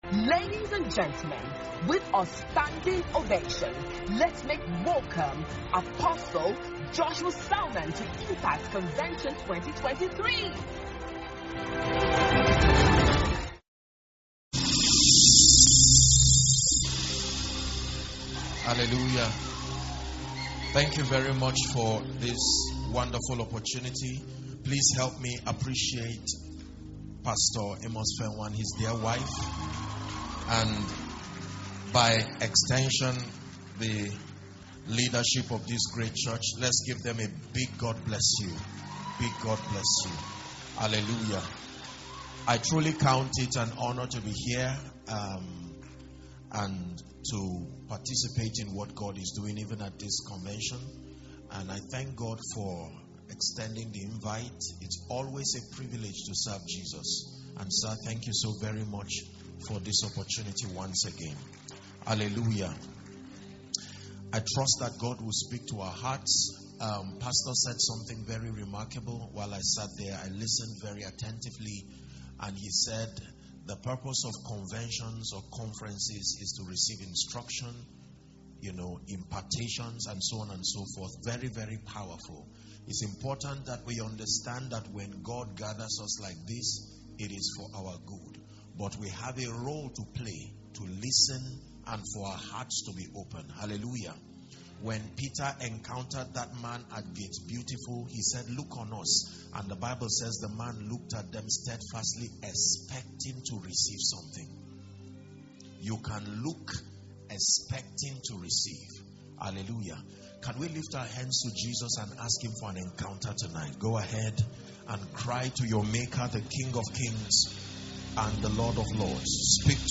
Impact Conference 2023 – HolyGhost Christian Centre